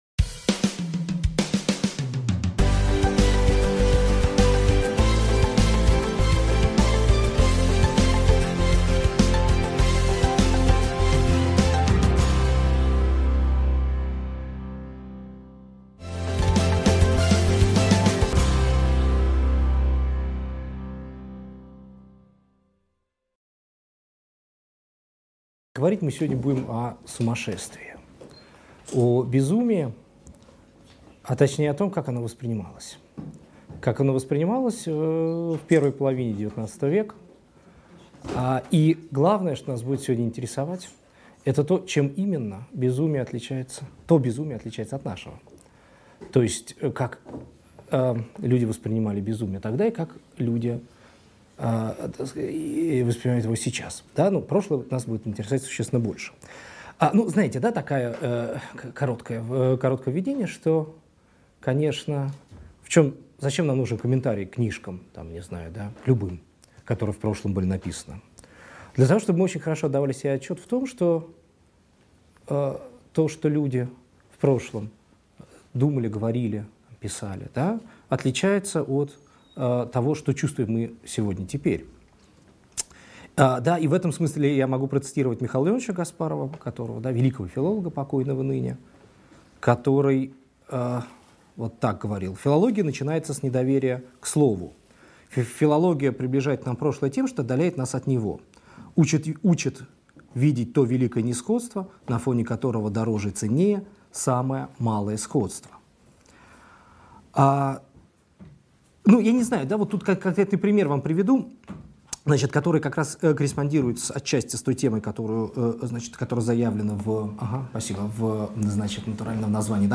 Аудиокнига Безумие в русской культуре и литературе первой половины XIX века | Библиотека аудиокниг